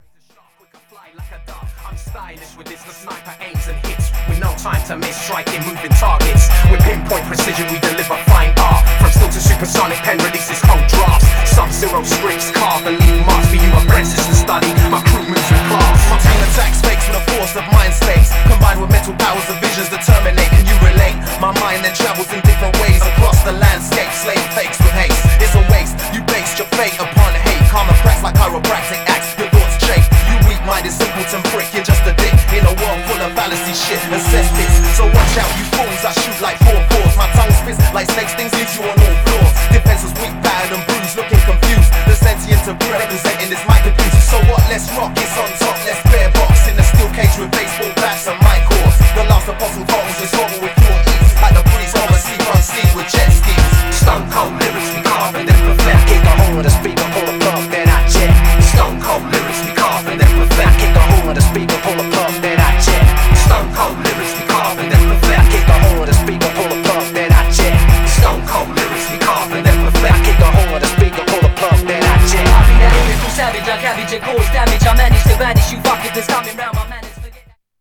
Styl: Hip Hop, Drum'n'bass